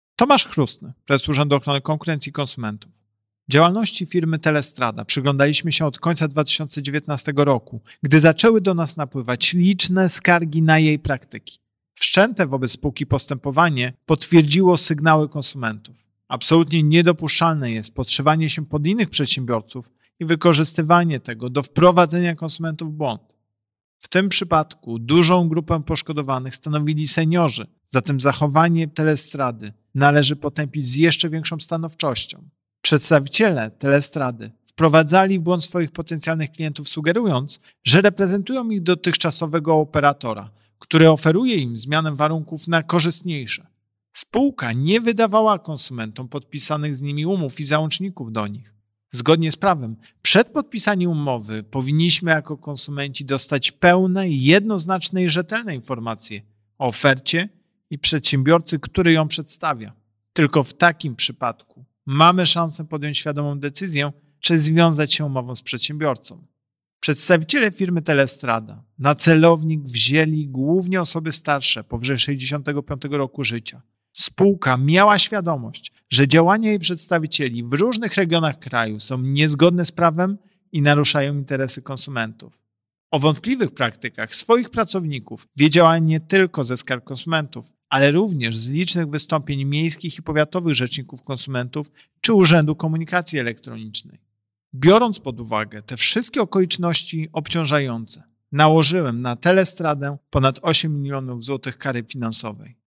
Wypowiedź Prezesa UOKiK Tomasza Chróstnego.mp3